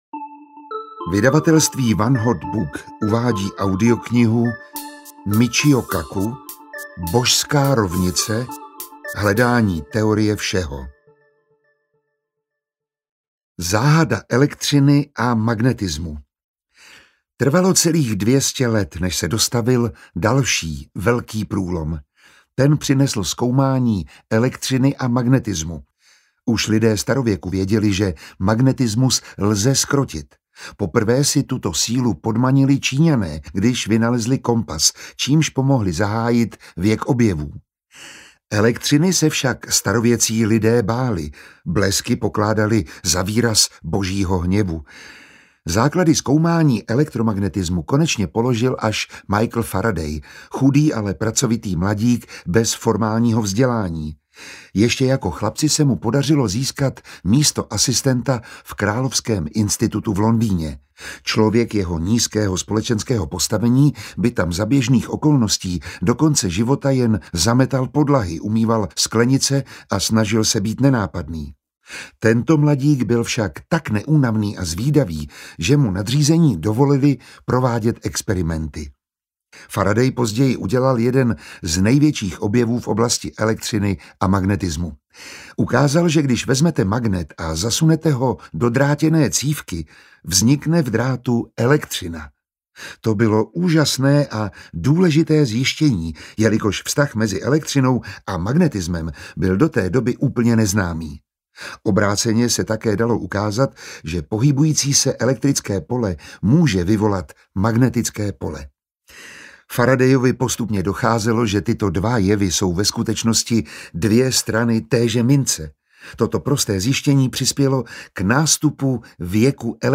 Božská rovnice: Hledání teorie všeho audiokniha
Ukázka z knihy
• InterpretMiroslav Táborský